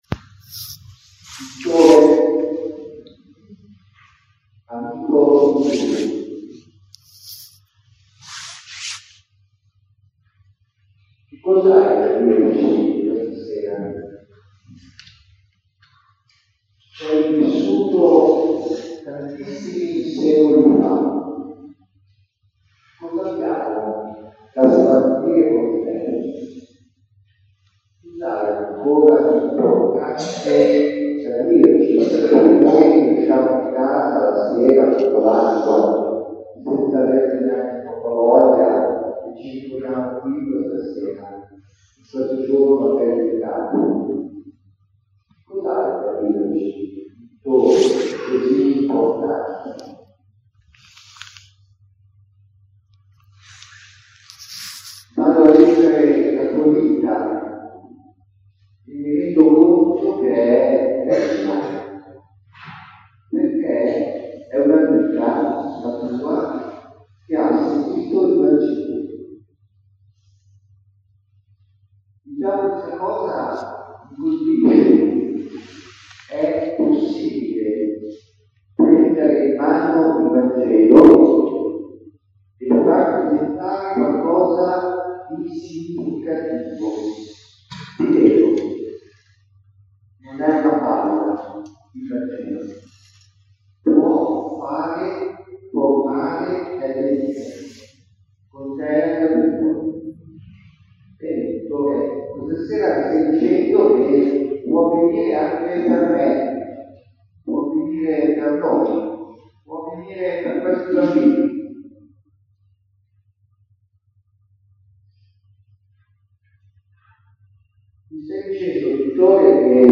Omelia San Vittore 2018
Ascolta l'omelia della Celebrazione Eucaristica in Occasione della Festa patronale della Parrocchia di Bedero Valtravaglia (file .mp3)(purtroppo l'audio non è dei migliori ce ne scusiamo)